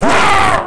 death3.wav